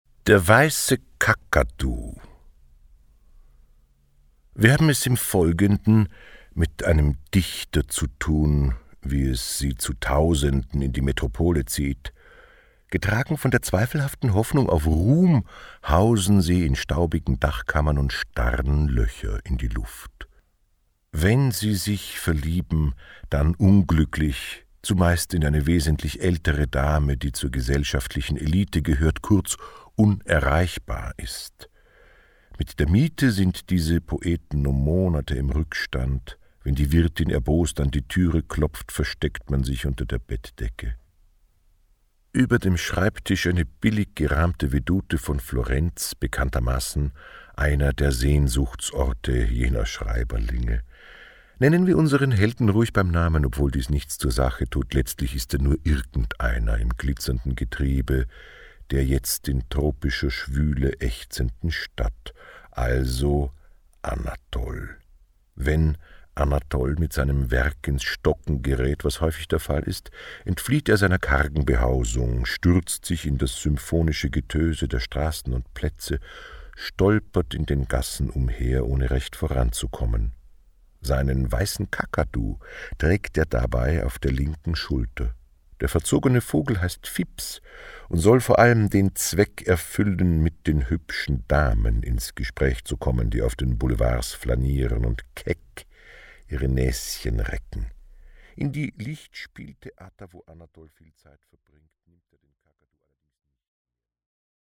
Aus dem Nichts kann man alles machen kann man aus dem Nichts (Hörbuch – Mp3-Download)